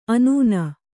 ♪ anūna